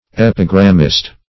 Epigrammist \Ep"i*gram`mist\